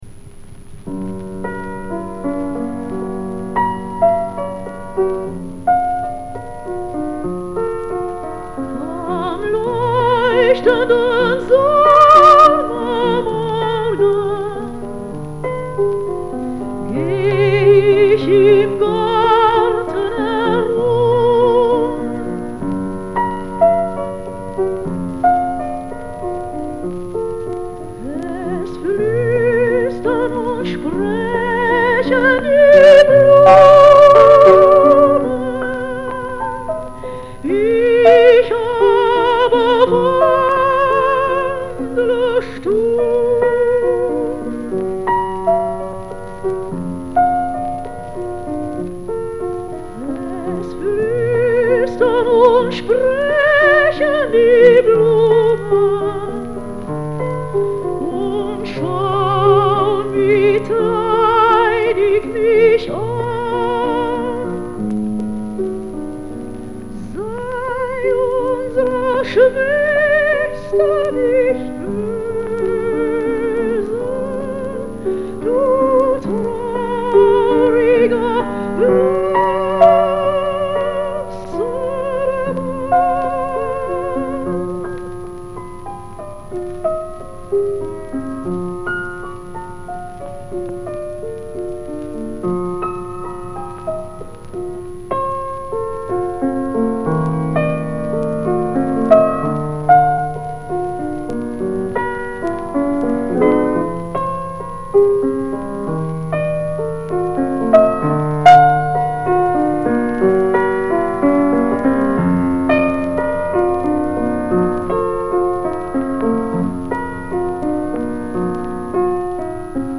Вокальный цикл
фортепиано